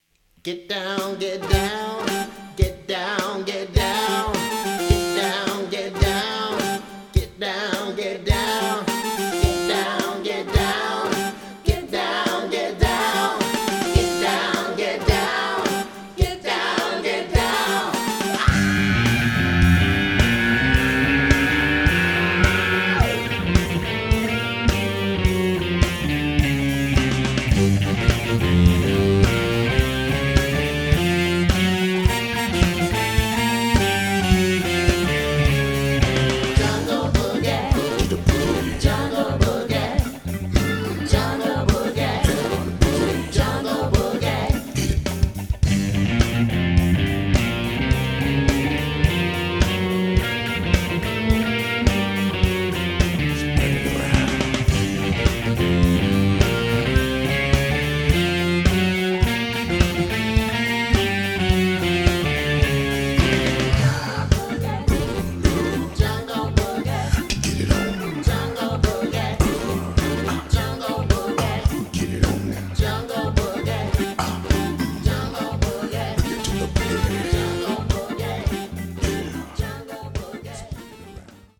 the ultimate party show band